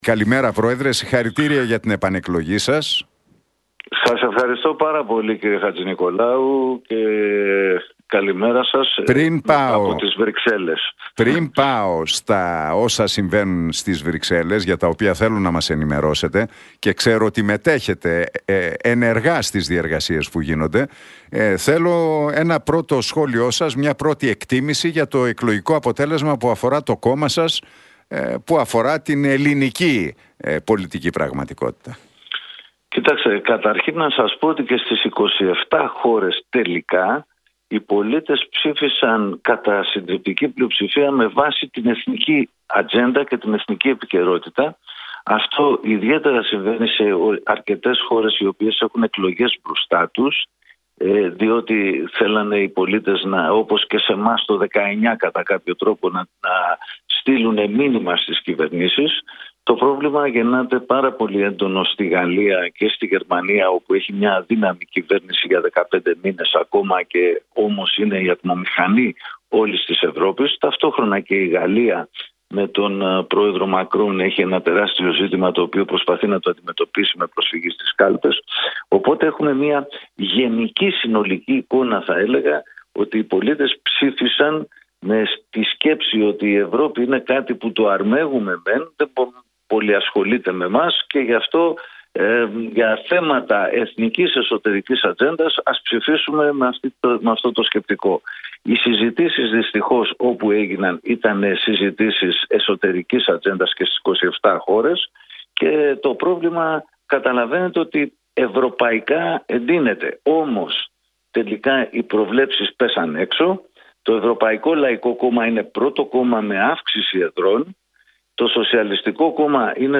«Και στις 27 χώρες οι πολίτες ψήφισαν κατά συντριπτική πλειοψηφία με βάση την εθνική ατζέντα και επικαιρότητα» δήλωσε ο αντιπρόεδρος του ΕΛΚ Βαγγέλης Μεϊμαράκης, μιλώντας στην εκπομπή του Νίκου Χατζηνικολάου στον Realfm 97,8.